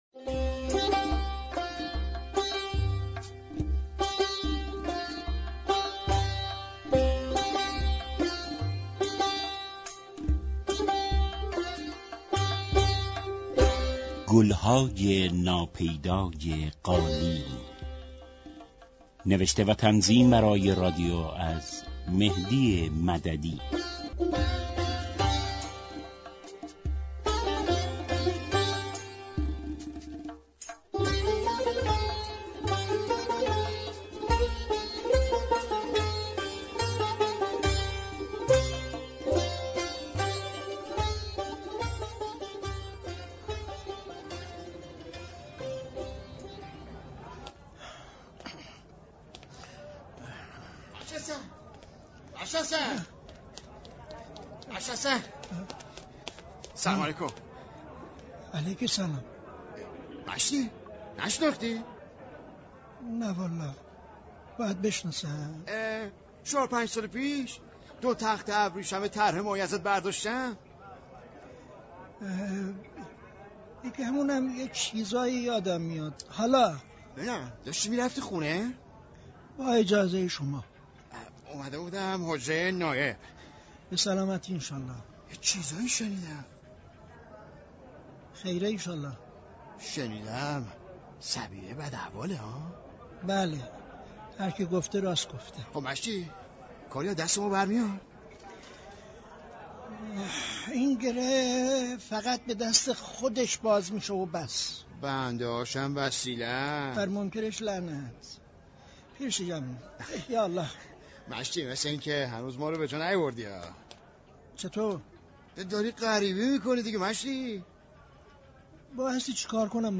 نمایش رادیویی